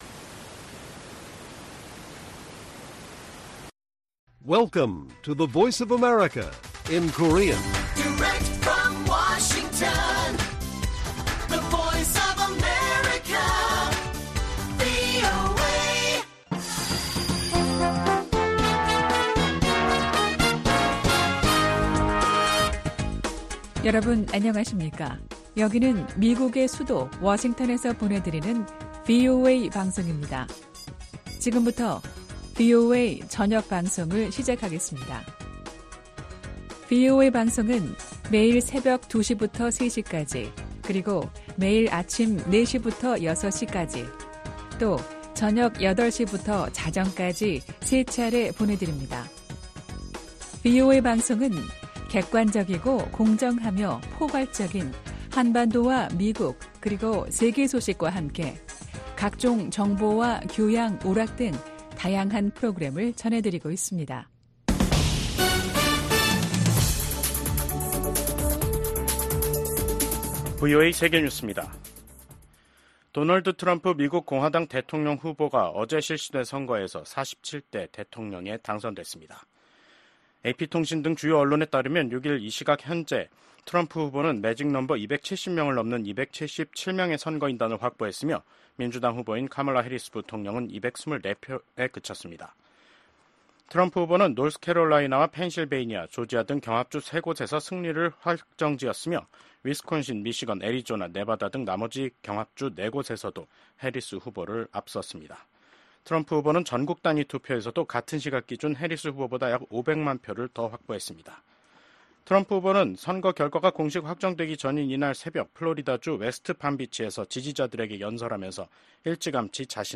VOA 한국어 간판 뉴스 프로그램 '뉴스 투데이', 2024년 11월 6일 1부 방송입니다. 2024년 미국 대선은 공화당 후보인 도널드 트럼프 전 대통령이 민주당 후보인 카멀라 해리스 부통령을 크게 앞서면서, 47대 대통령에 당선됐습니다.